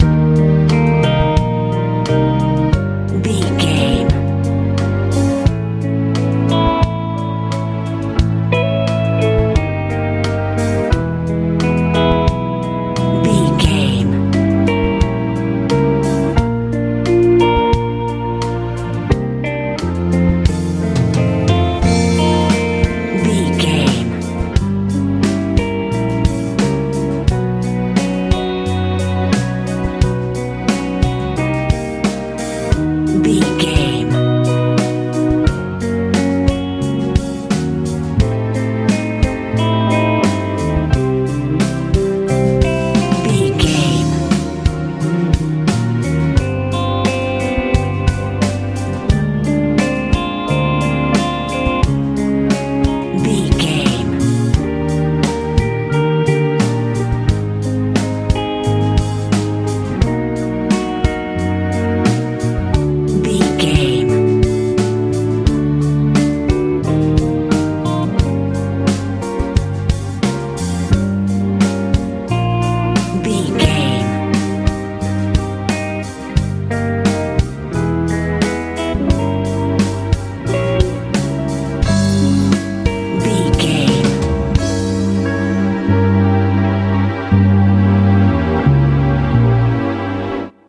80s pop ballad feel
Ionian/Major
E♭
joyful
synthesiser
electric guitar
piano
bass guitar
drums
mellow
relaxed